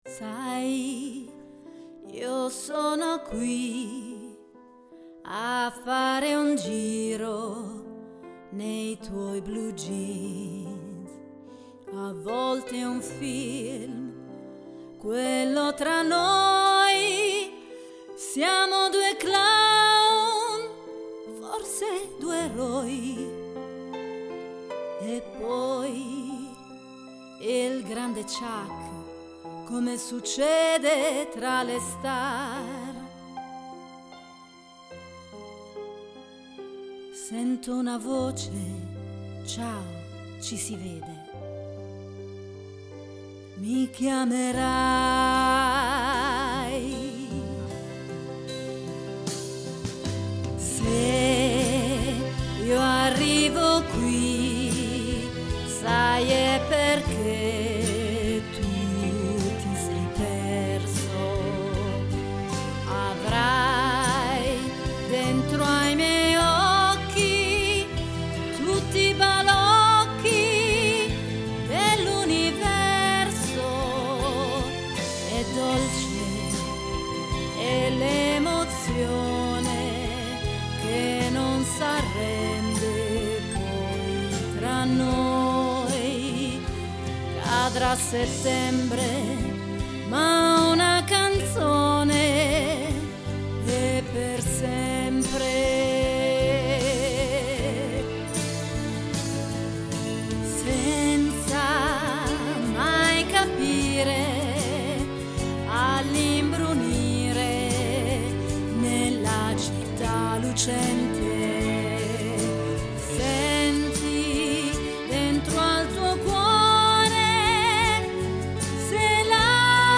Una canzone per Natale, non banale, scritta da me con Logic.
(allegato testo e provino )